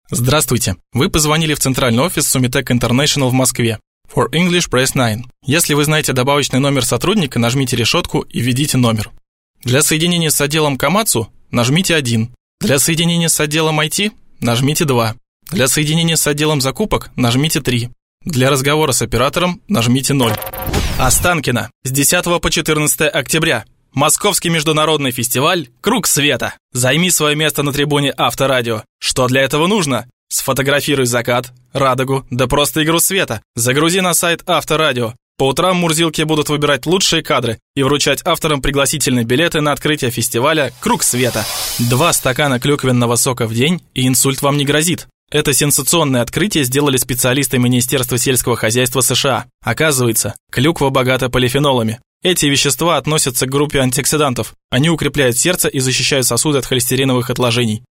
Начинающий диктор, нравится работать с закадровой озвучкой.
Shure sm-58